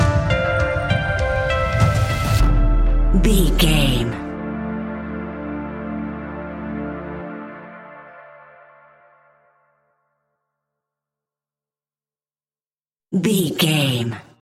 Aeolian/Minor
D
ominous
dark
dramatic
eerie
synthesiser
drums
percussion
horror music